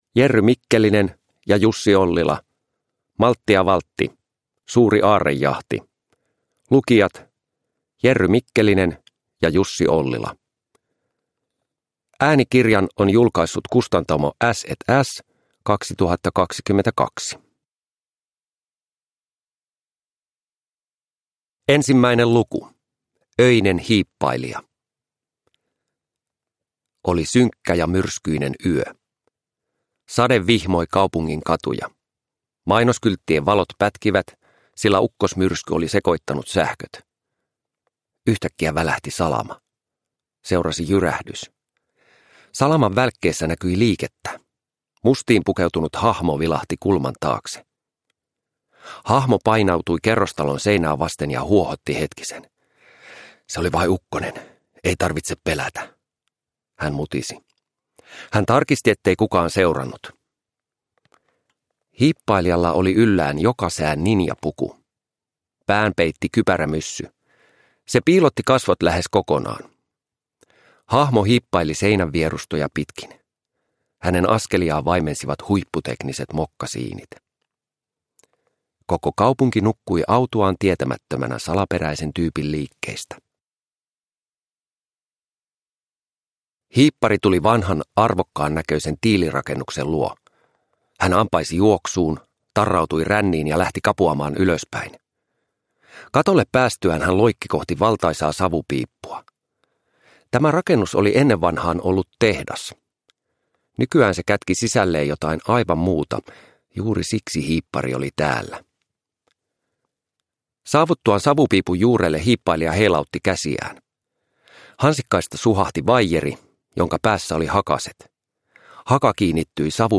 Maltti ja Valtti - Suuri aarrejahti – Ljudbok – Laddas ner